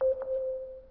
misslebeep.wav